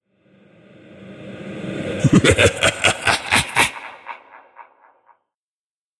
На этой странице собраны звуки безумия: маниакальный смех, невнятные крики, стоны и другие проявления психоза.
Мужской зловещий